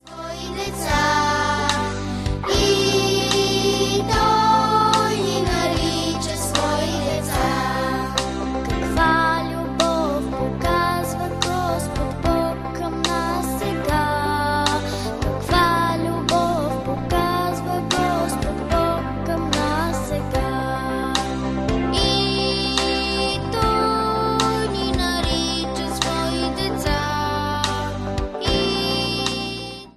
Инструментали на всички песни...